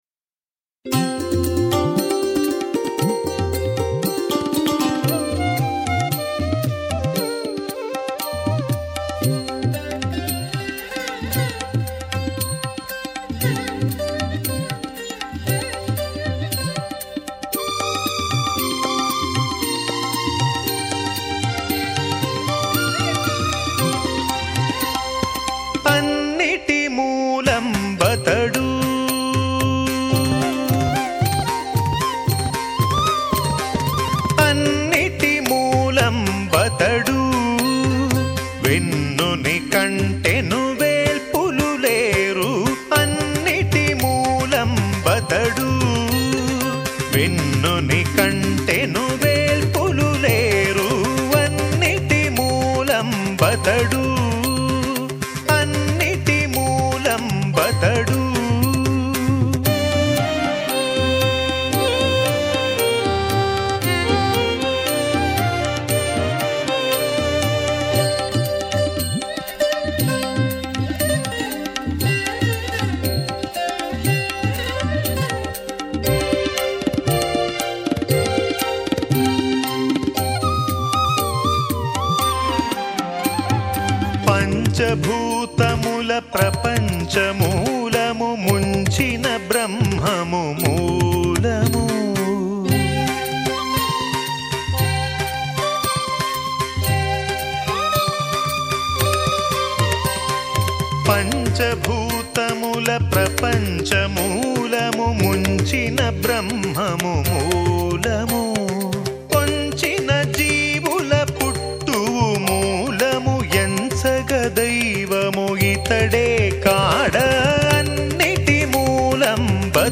సంకీర్తన